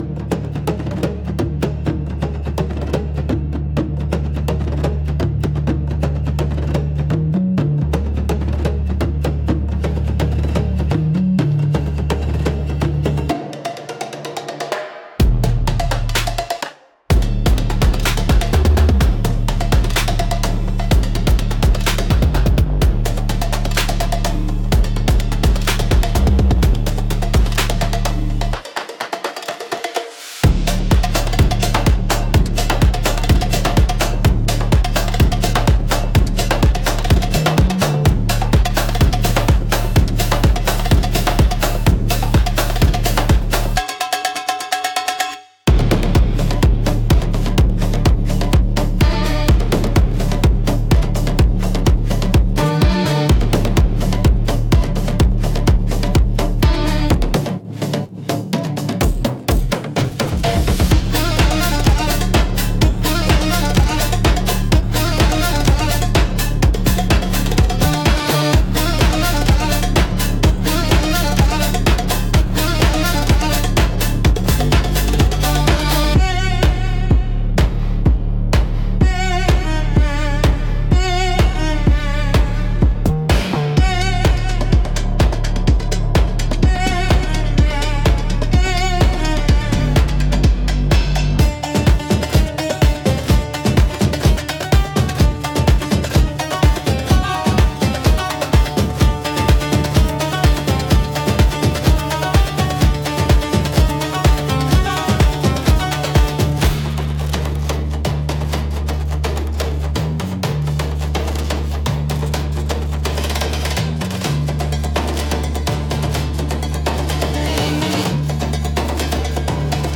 Instrumental - The Fire Beneath Silk 2.49